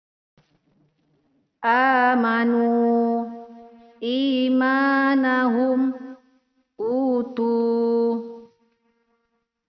Cara membacanya Panjang 2 harokat :